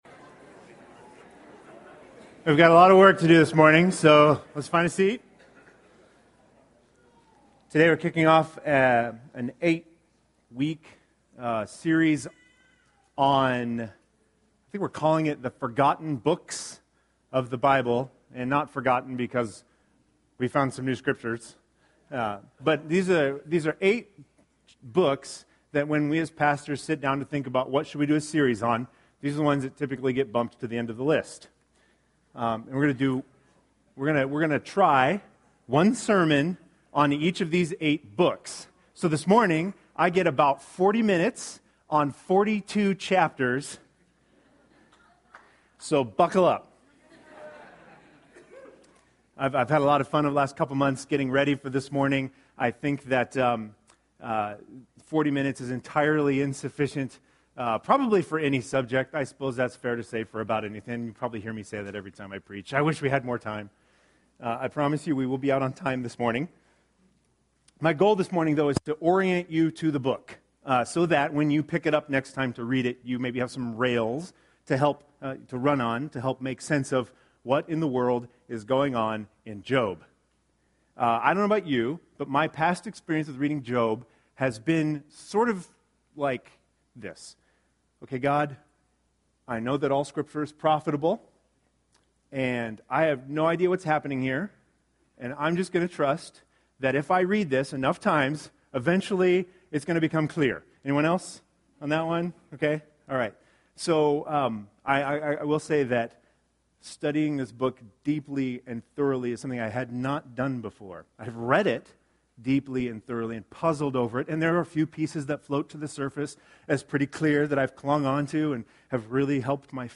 I get to kick us off with a single sermon on 42 chapters of the book of Job.